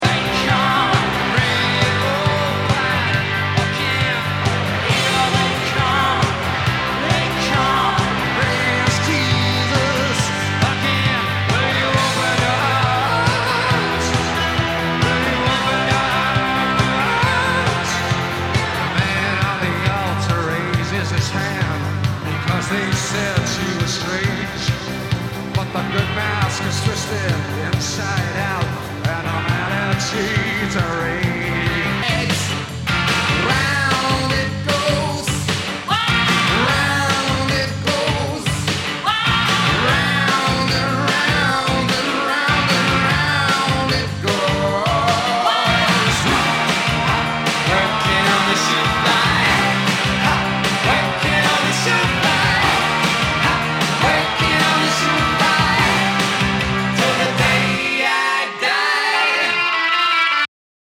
[ Genre ] ROCK/POPS/INDIE
ナイス！オルタナティブ！